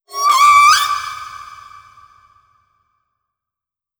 khloCritter_Female17-Verb.wav